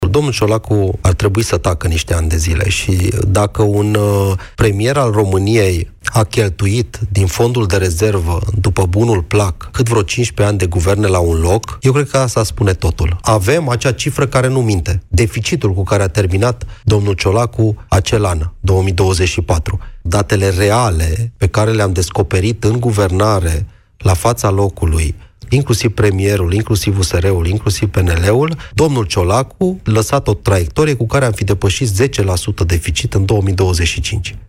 Ministrul Economiei, Irineu Darău, a declarat la emisiunea Piața Victoriei că este o corecție necesară, după ani de cheltuieli excesive.